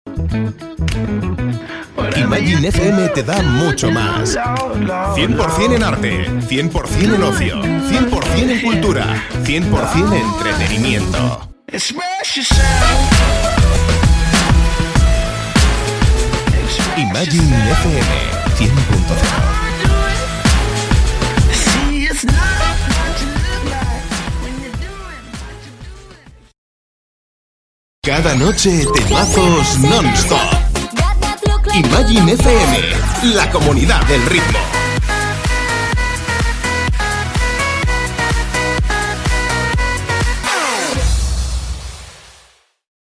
VOZ MASCULINA, POTENTE, ELEGANTE, SUGERENTE Y DUCTIL.
kastilisch
Sprechprobe: Industrie (Muttersprache):
Masculine, smart, evocative voice with character and ductile.